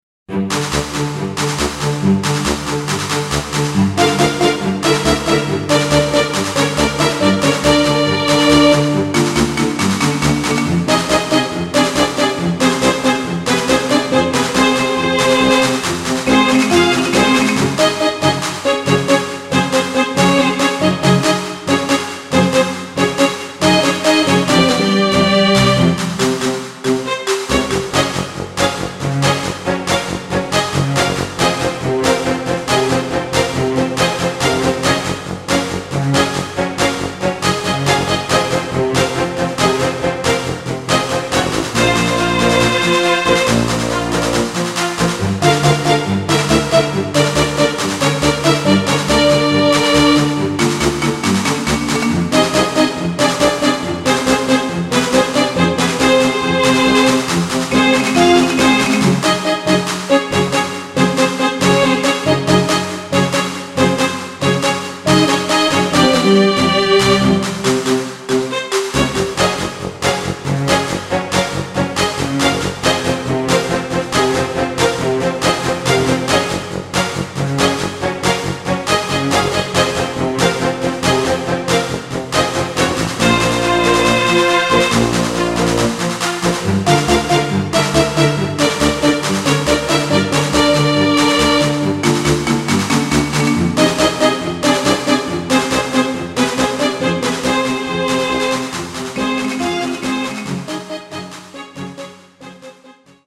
SC88Pro